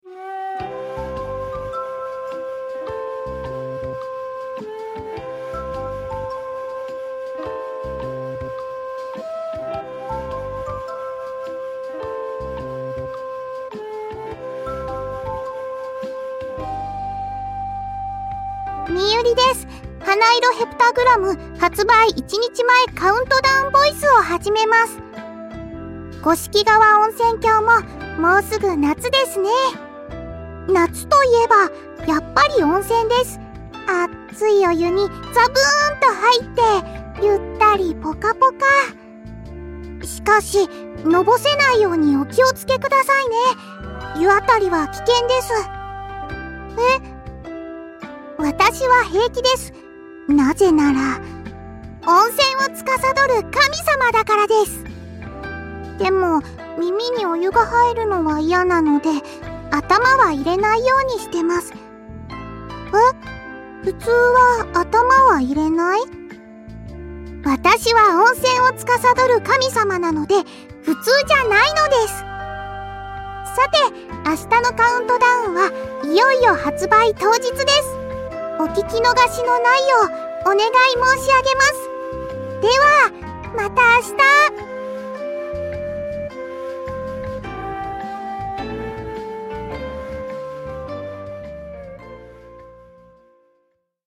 発売一日前カウントダウンボイス公開！